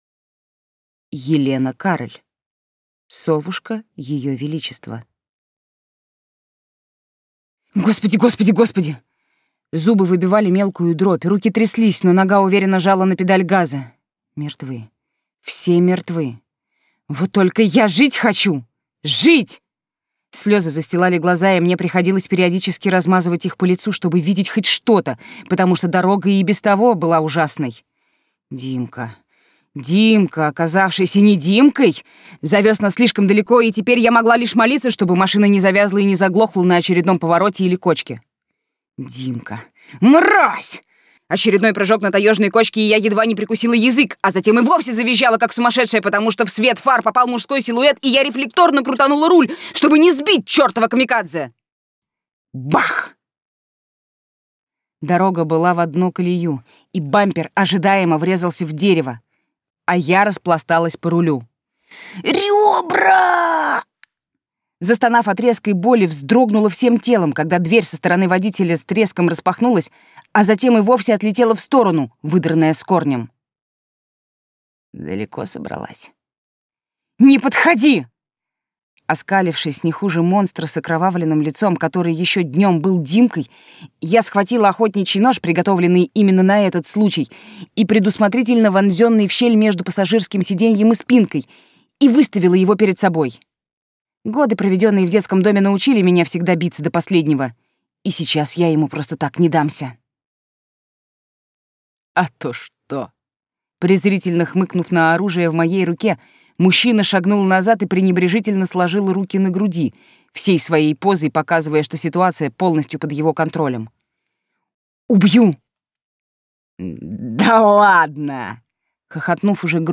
Аудиокнига Совушка её величества | Библиотека аудиокниг
Прослушать и бесплатно скачать фрагмент аудиокниги